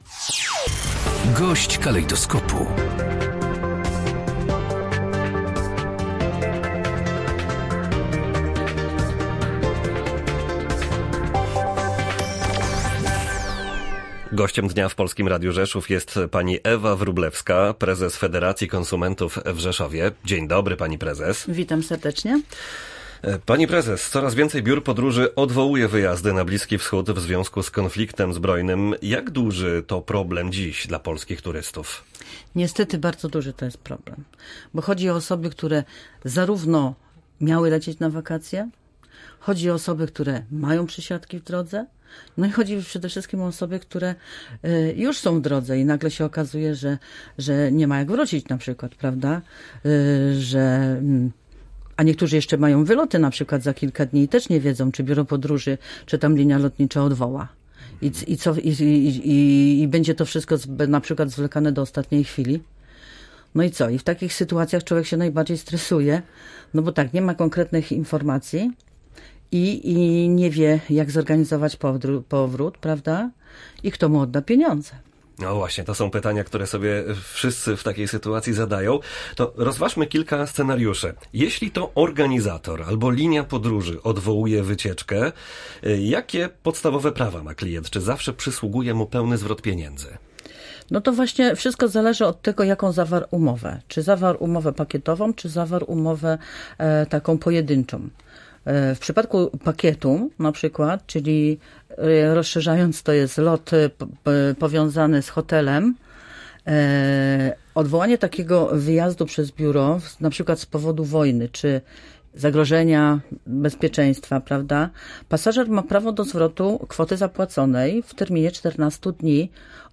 Gość dnia • Coraz więcej biur podróży odwołuje wyjazdy na Bliski Wschód w związku z konfliktem zbrojnym. To problem nie tylko osób planujących wakacje, ale także tych, którzy są już w podróży.